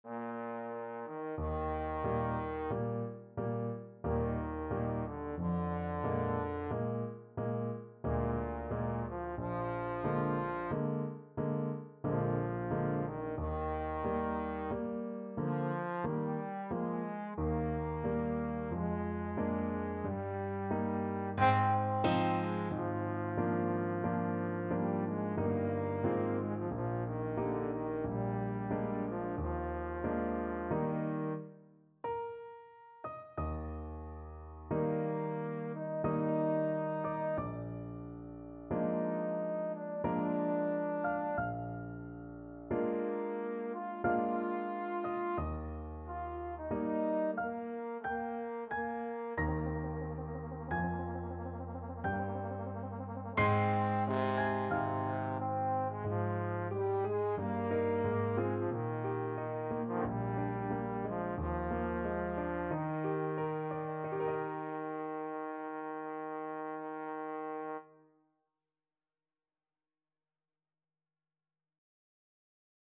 Trombone version
3/4 (View more 3/4 Music)
Bb3-F5
Adagio =45
Classical (View more Classical Trombone Music)